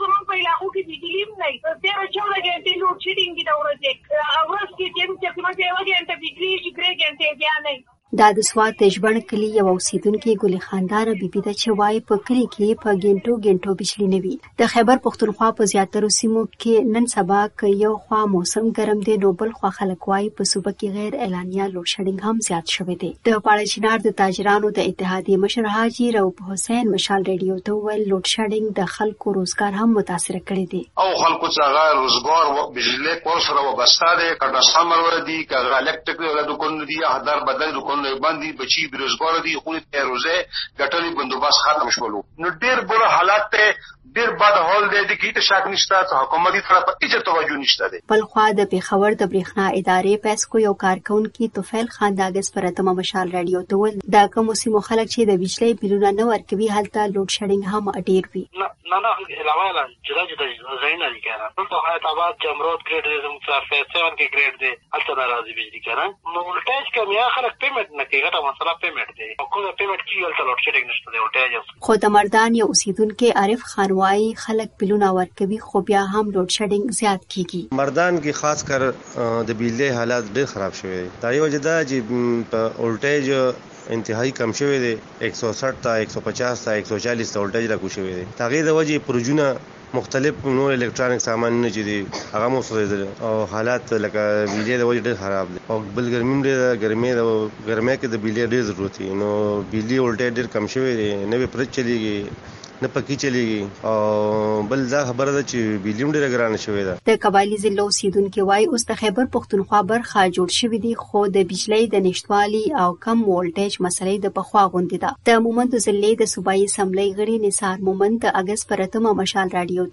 په خیبر پښتونخوا کې د بجلۍ پر لوډشېډنګ راپور دلته واورئ